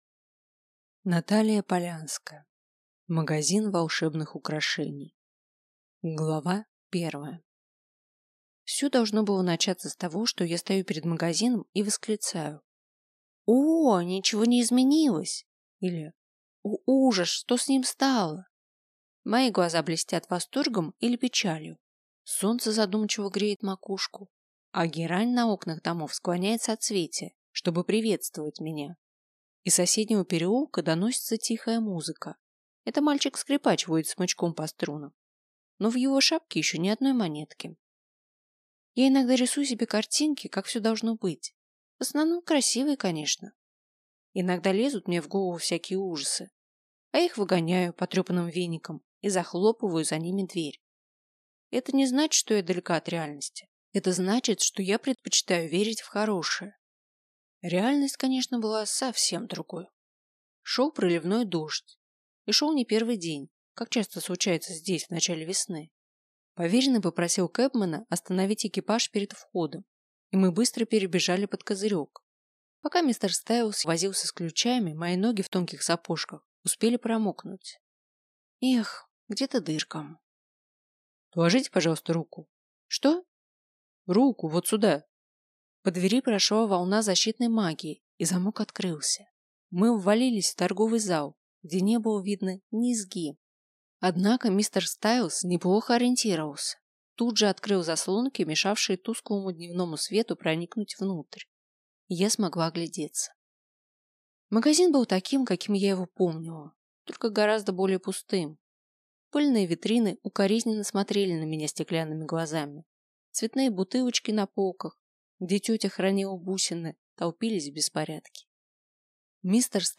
Аудиокнига Магазин волшебных украшений | Библиотека аудиокниг